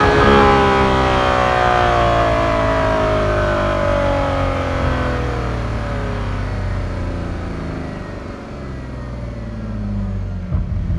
v12_07_decel.wav